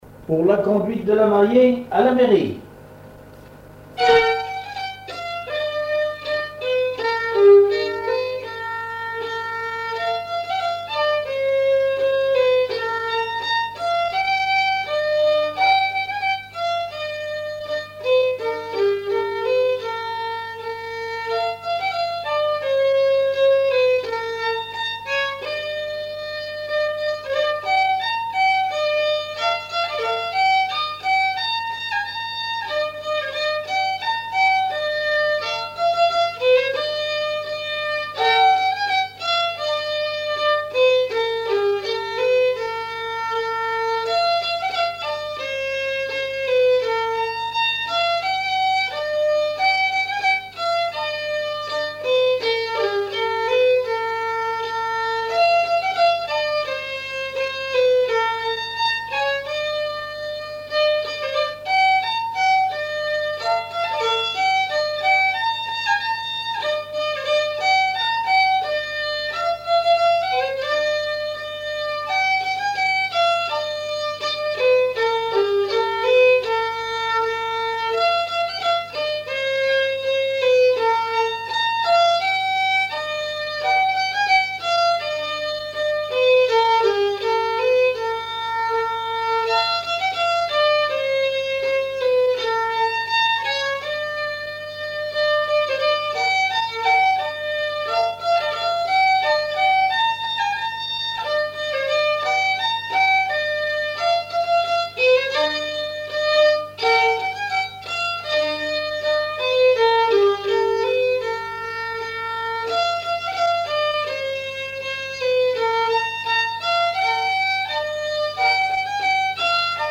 Marche de cortège de noce pour aller à la mairie
Auto-enregistrement
Pièce musicale inédite